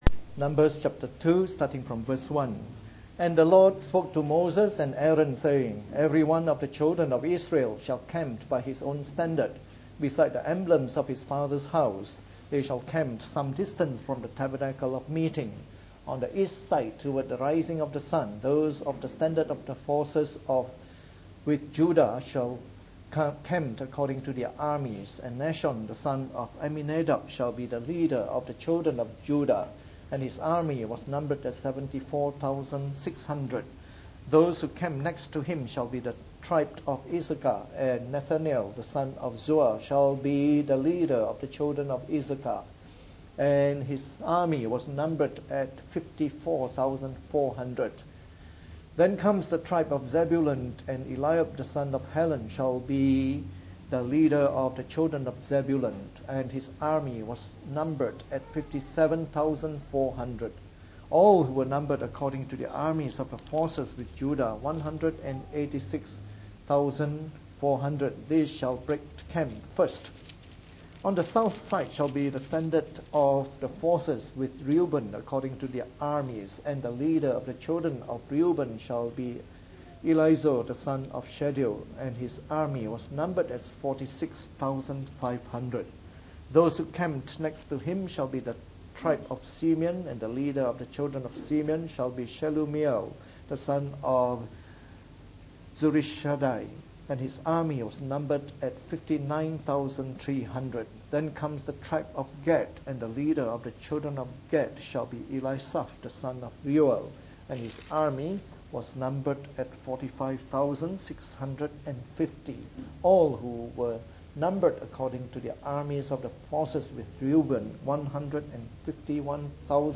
From our new series on the “Book of Numbers” delivered in the Morning Service.